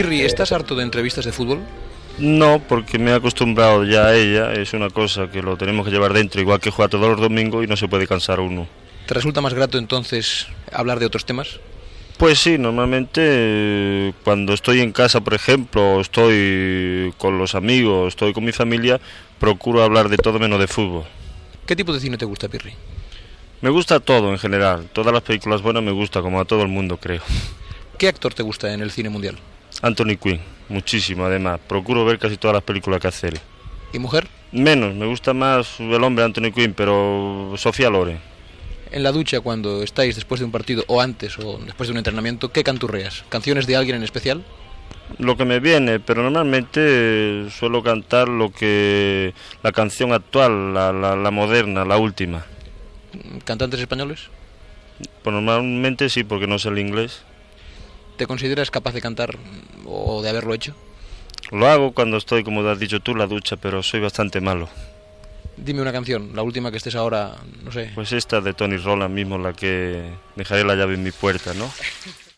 Entrevista al jugador de futbol del Real Madrid "Pirri" (José Martínez Sánchez )